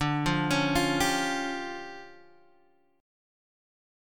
DmM7bb5 chord